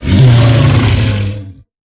Sfx_creature_snowstalker_distantcall_01.ogg